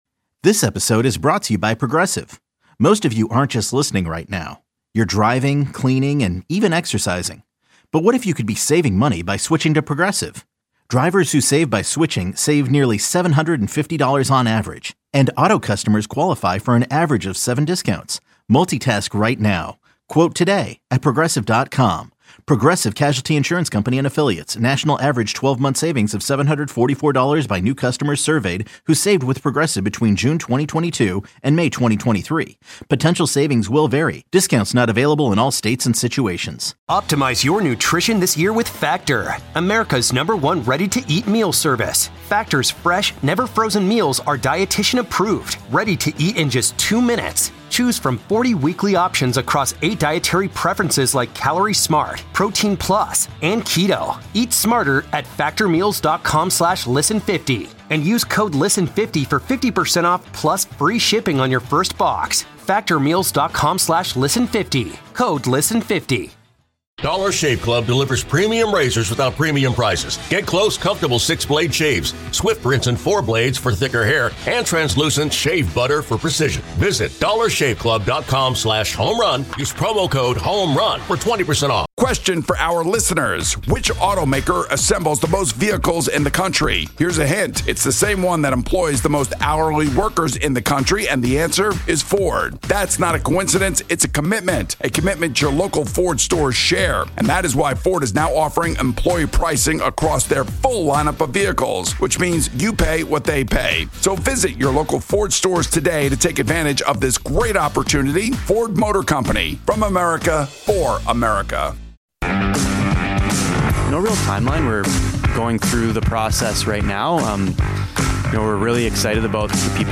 The show includes interviews with the top beat writers in the city as well as team executives, coaches and players.